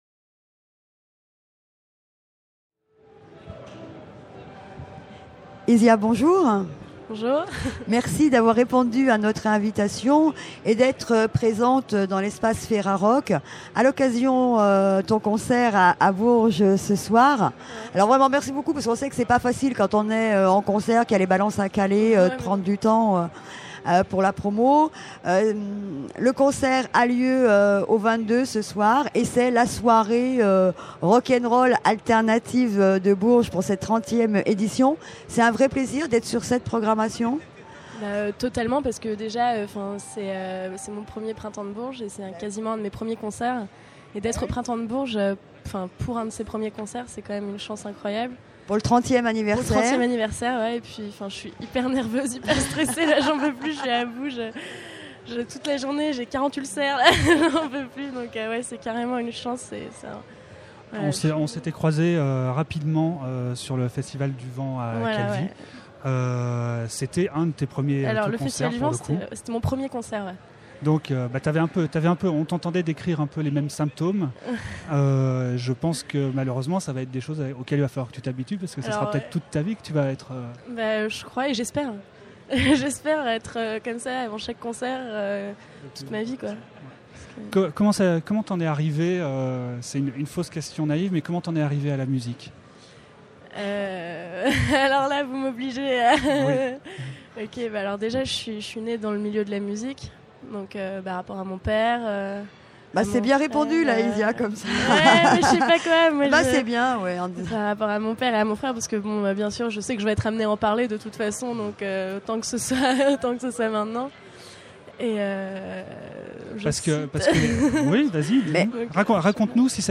Izia Festival du Printemps de Bourges 2006 : 40 Interviews à écouter !